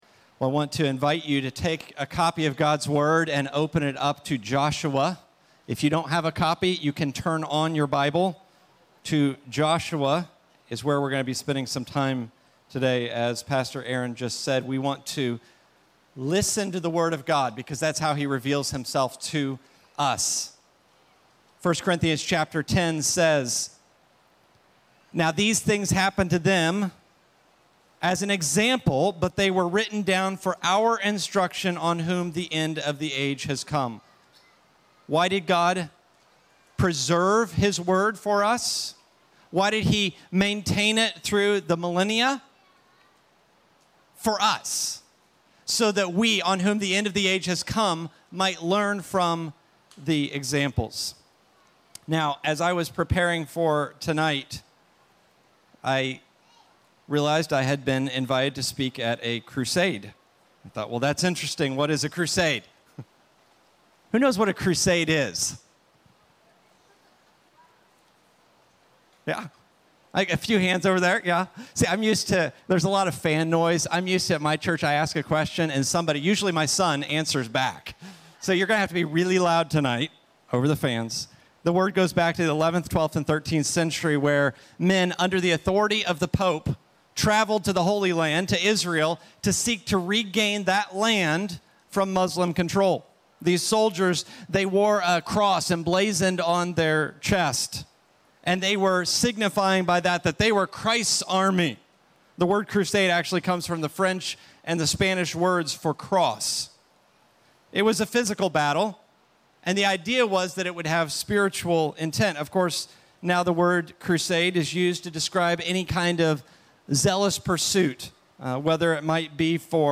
Finding Victory - Stand Alone Messages ~ Free People Church: AUDIO Sermons Podcast